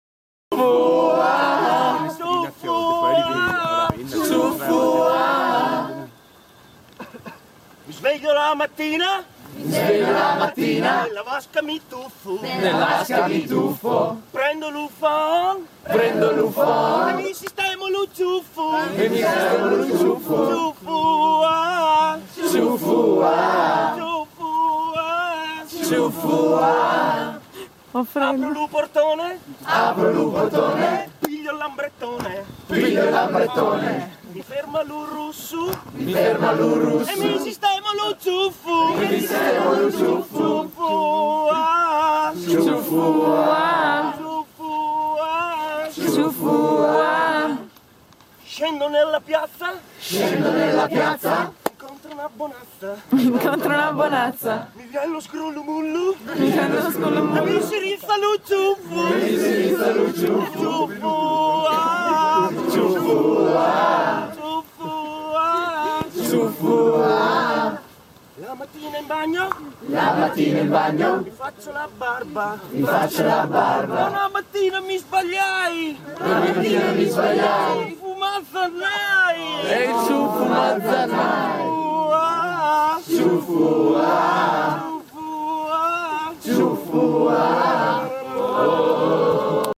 Ban a botta e risposta